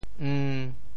潮州 eng1 文 对应普通话: ēn 潮州 ng1 又 对应普通话: ēn ①德泽；好处。
ng1.mp3